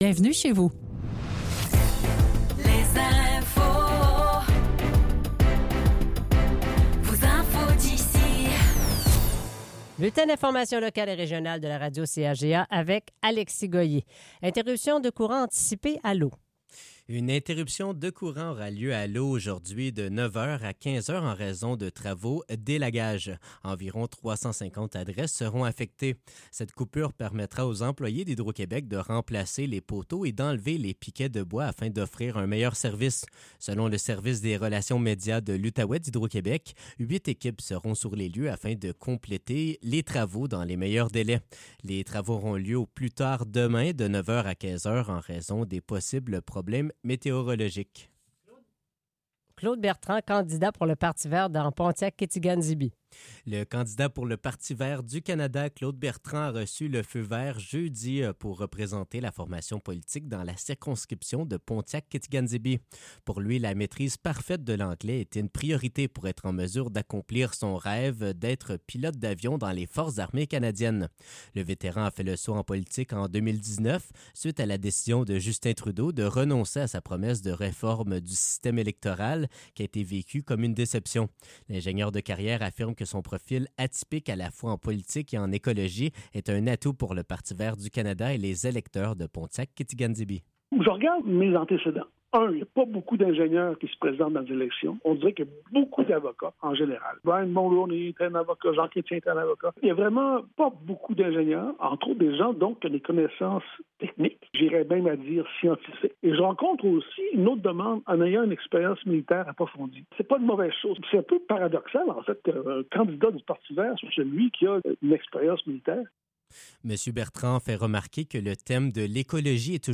Nouvelles locales - 8 avril 2025 - 8 h